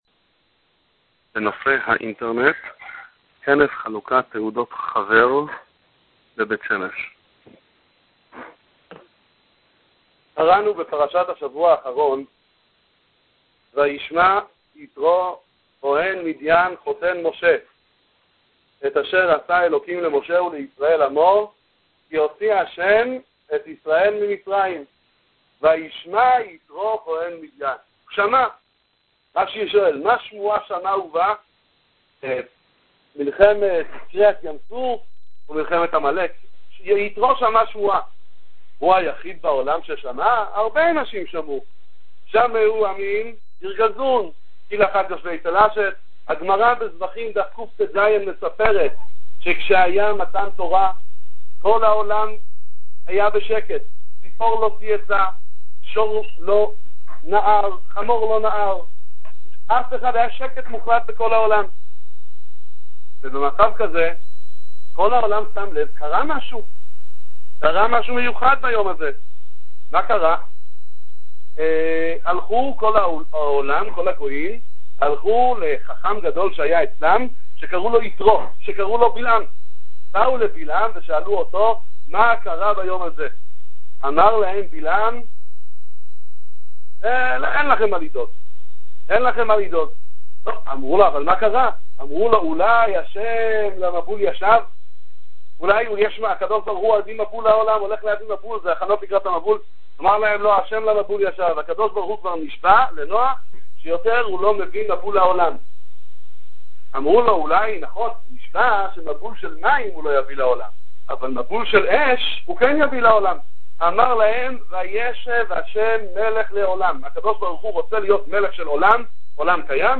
בהירות שיעורי תורה
בית המדרש משאת מרדכי רמב"ש א'